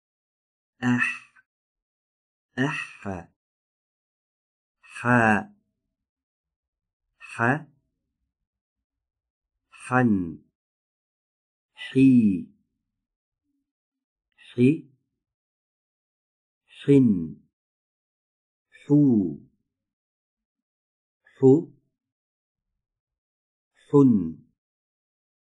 در زبان عربی حرف «حاء» از وسط حلق به تلفظ در می‌آید.
👌برای تولید این حرف، وسط حلق کمی تنگ‌تر می‌شود و هوای سازنده آن، از مجرای باریک ایجاد شده، به صورت سایشی خارج می‌شود؛ بدون آنکه تارهای صوتی را مرتعش سازد؛ با گرفتگی صدا که ویژه حرف «حاء» می‌باشد؛ صدای آن تولید می‌شود.